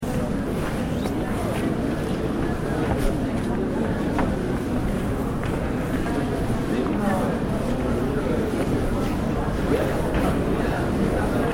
دانلود صدای ایستگاه مترو از ساعد نیوز با لینک مستقیم و کیفیت بالا
جلوه های صوتی